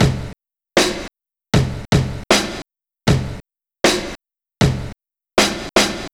Track 11 - Kick Snare Beat 05.wav